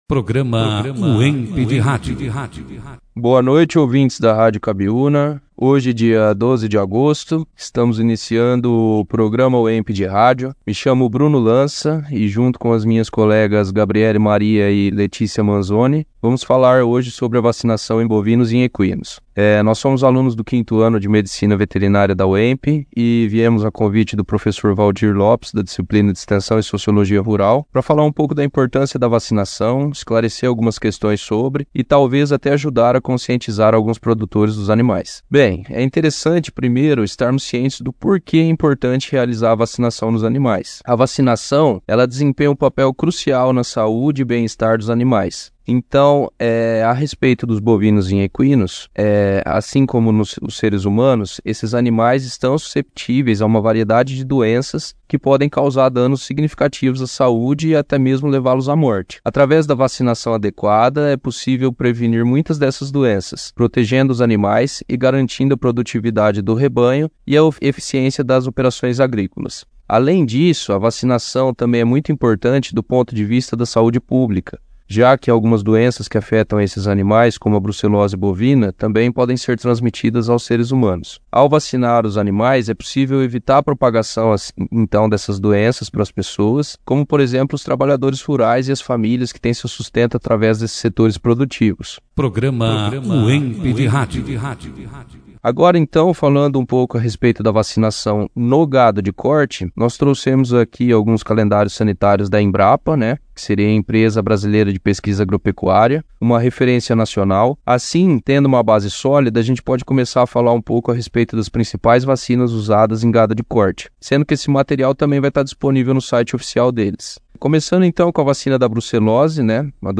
Produzido e apresentado pelos alunos, Acadêmicos do 5º ano do curso Medicina Veterinária